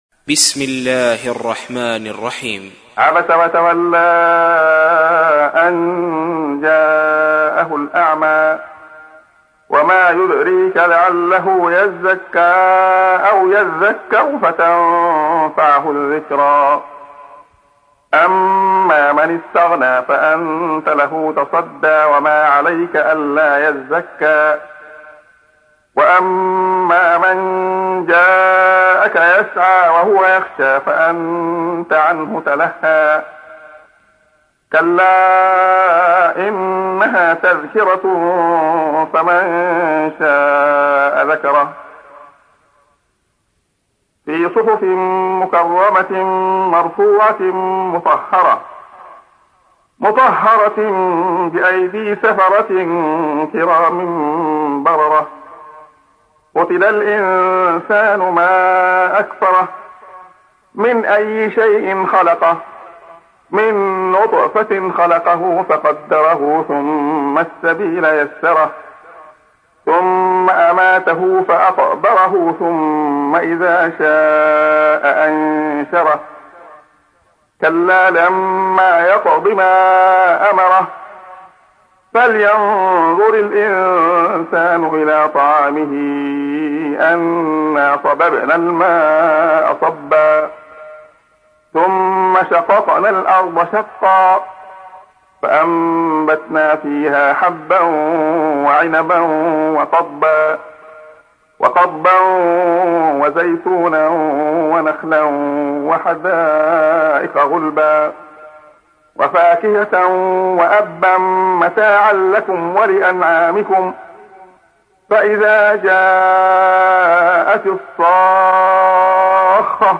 تحميل : 80. سورة عبس / القارئ عبد الله خياط / القرآن الكريم / موقع يا حسين